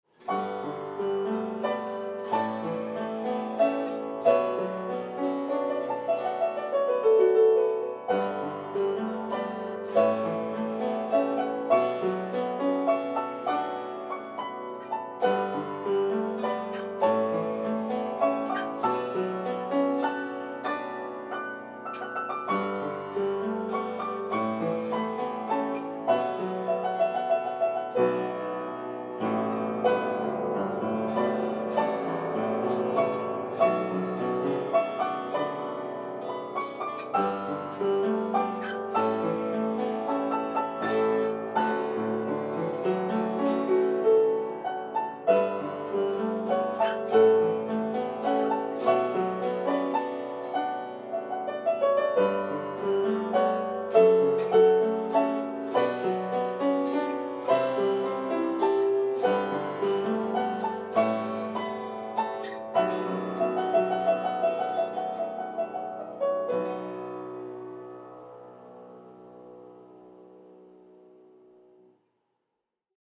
ギター